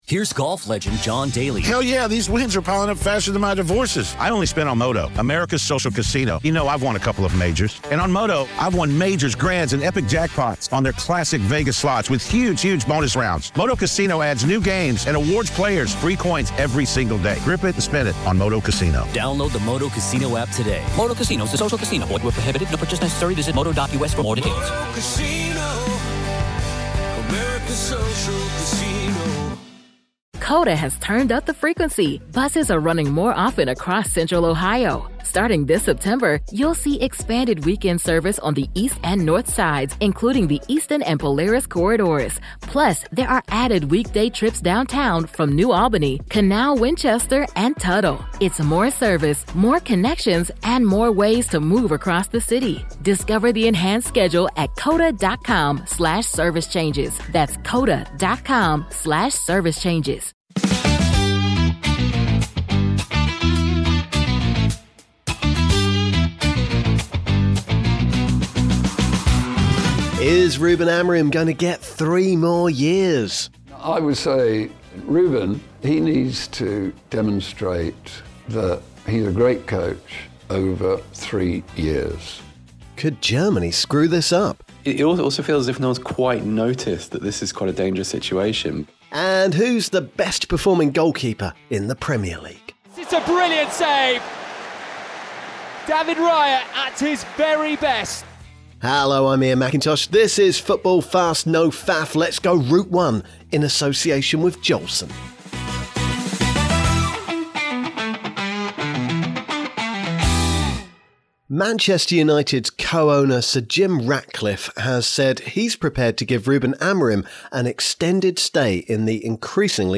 This time, we’ve plugged the guitar in…